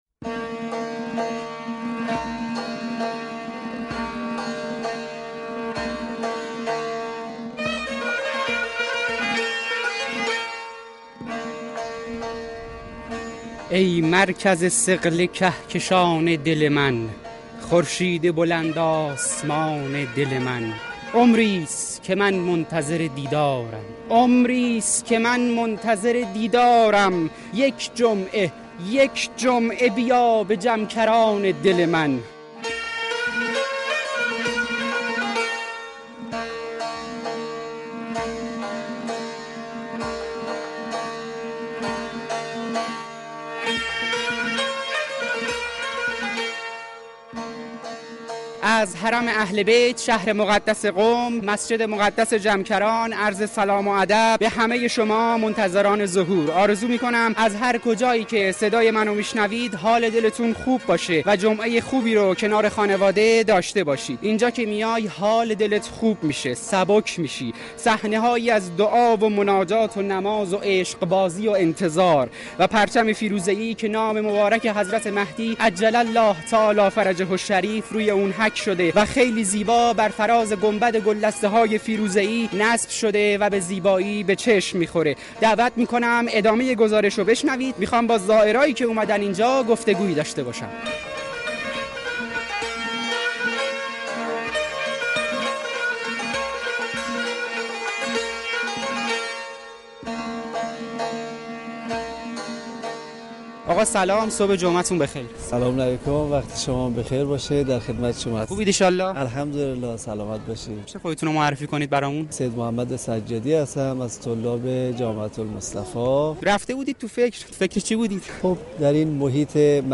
پادکست گزارشی از مسجد مقدس جمکران و گفت و گو با زائران و مجاوران درباره مهدویت و انتظار